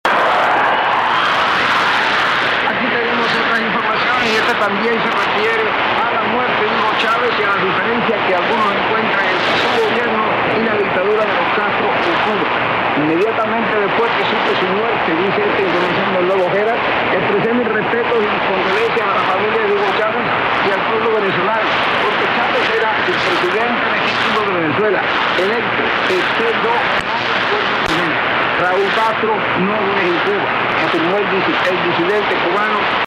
Radios used are Grundig S350 and Grundig G8 Traveller II.
Radio Republica 9490 Montsinéry 3-10-13 Spanish - signed off at 7:55 P MDT.